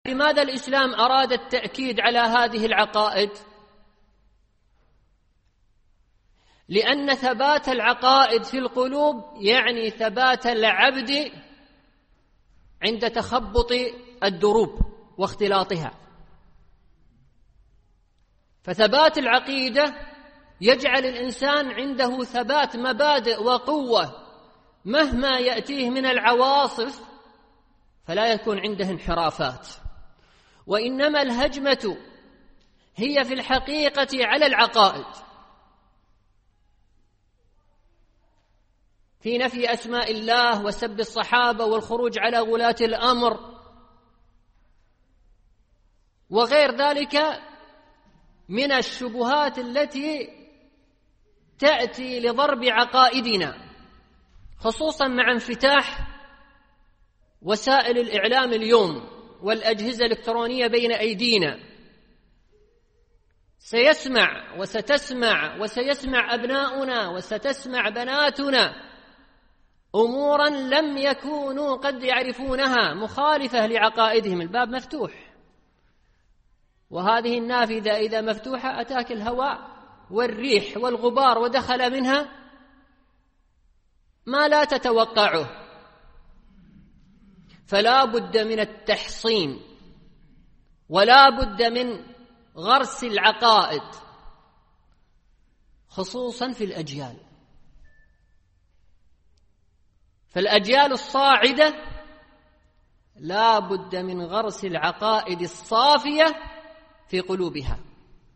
MP3 Mono 44kHz 32Kbps (CBR)